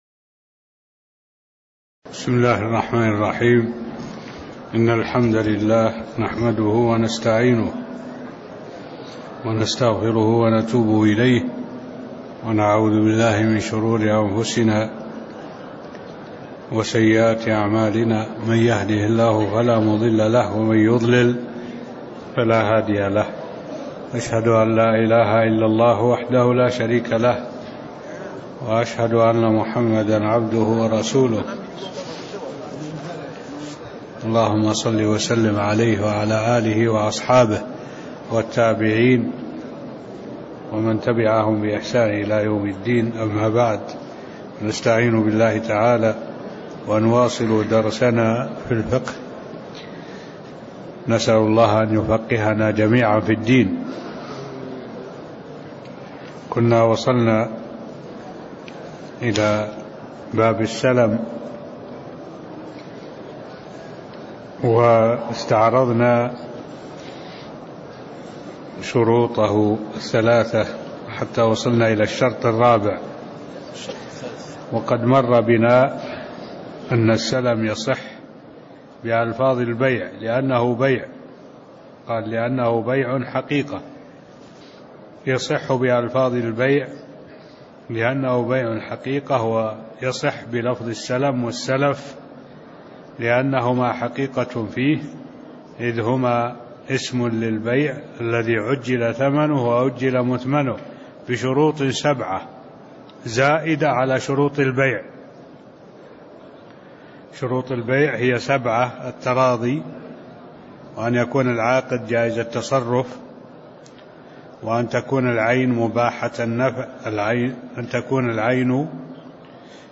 المكان: المسجد النبوي الشيخ: معالي الشيخ الدكتور صالح بن عبد الله العبود معالي الشيخ الدكتور صالح بن عبد الله العبود تكملة باب السلم (الشرط الرابع) (03)